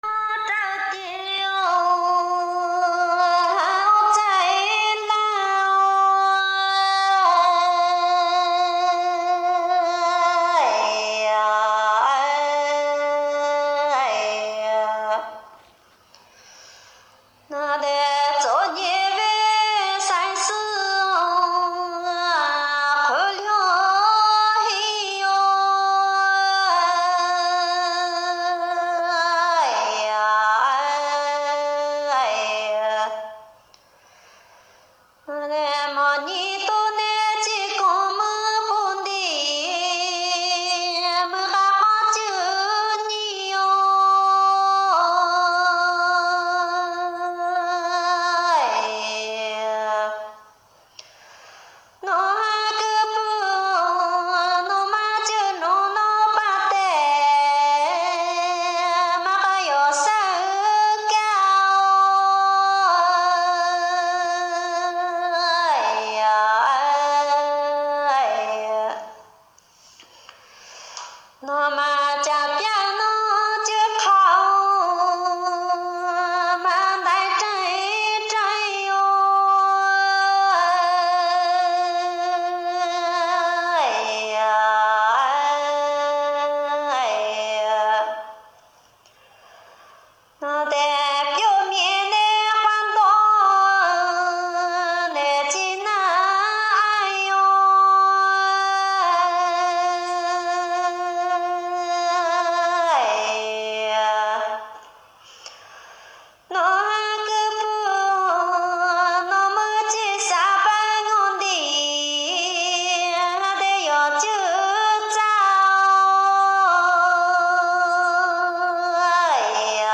女声便酷腔.mp3